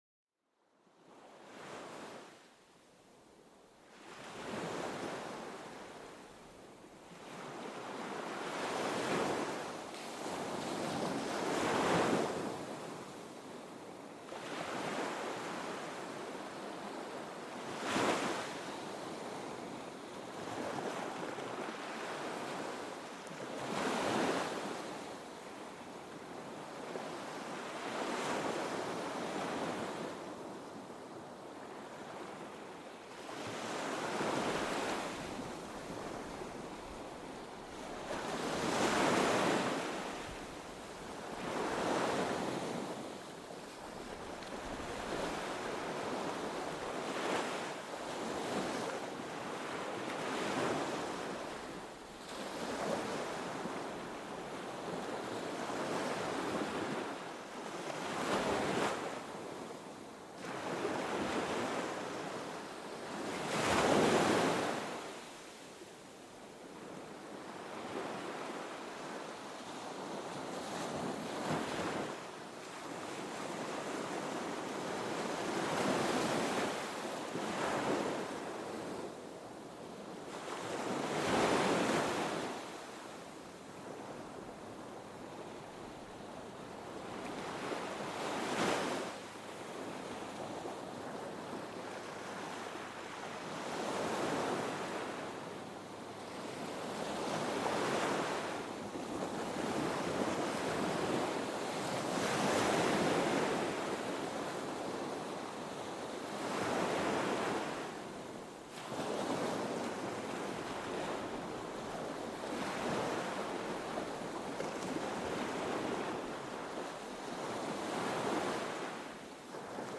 Sumérgete en la tranquilidad con el sonido calmante de las olas del océano. Este episodio te lleva a una playa solitaria, donde el único sonido es el suave choque de las olas contra la orilla.